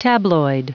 Prononciation du mot tabloid en anglais (fichier audio)
Prononciation du mot : tabloid